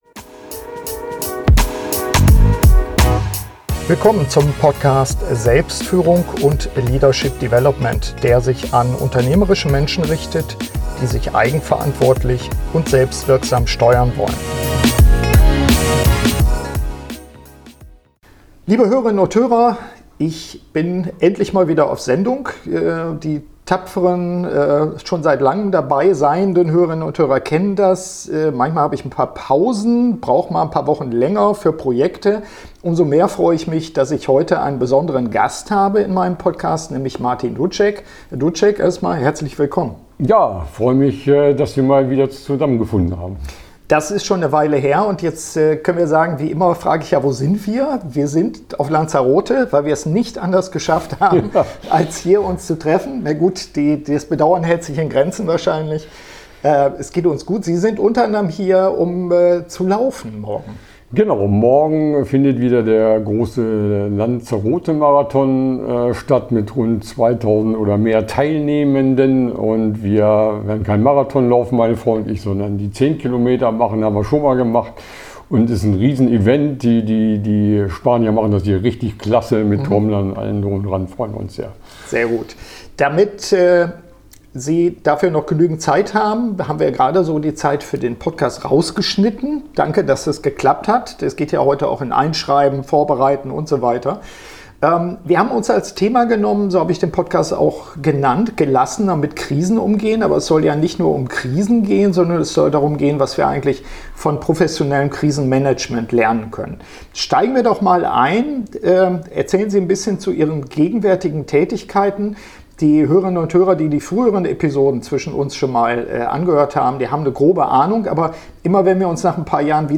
Beschreibung vor 2 Jahren Sorry, dass es schon wieder um Krisen geht - aber heute wollen wir von einem Profi hören, was wir aus dem Bereich der Luftfahrt lernen und auf unseren Führungsalltag übertragen können.